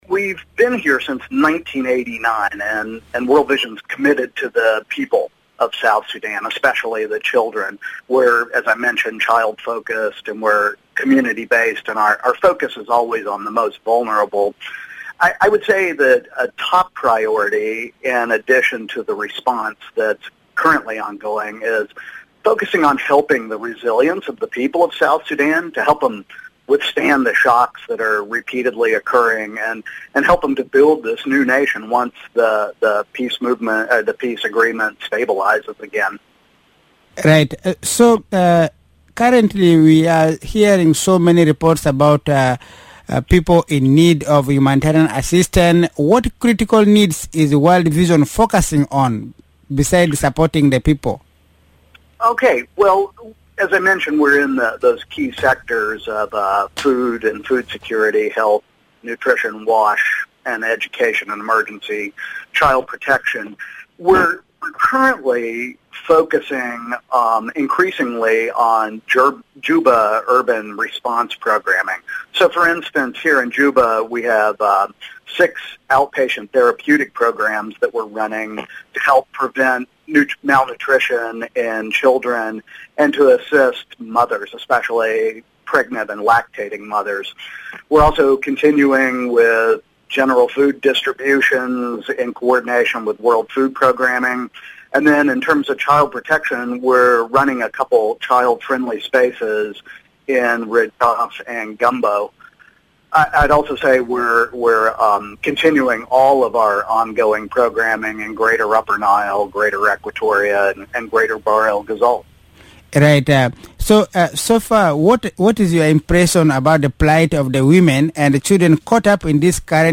Radio Miraya